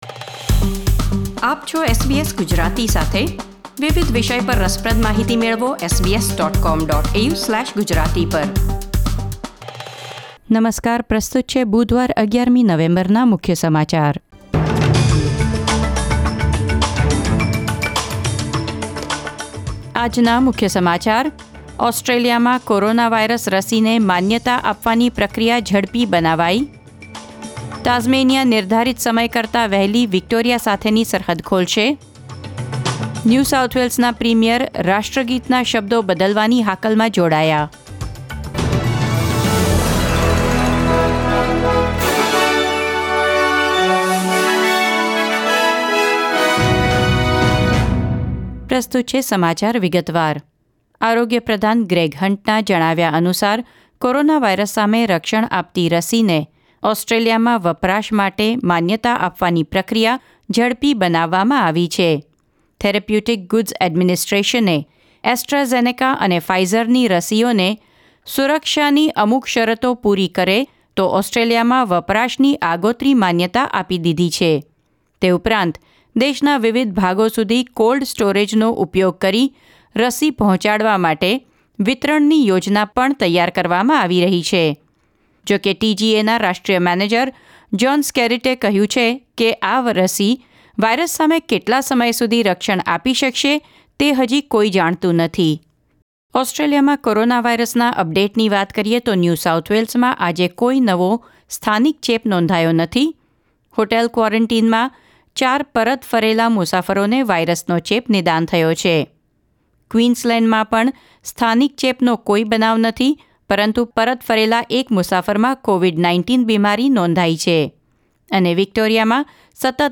SBS Gujarati News Bulletin 11 November 2020